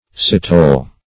Citole \Cit"ole\, n. [OF. citole, fr. L. cithara.